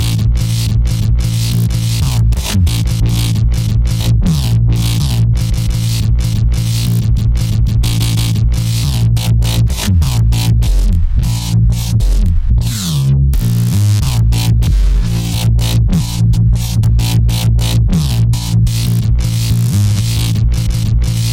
dubstep噪音，hip hop节奏。
Tag: 90 bpm Dubstep Loops Bass Wobble Loops 3.59 MB wav Key : Unknown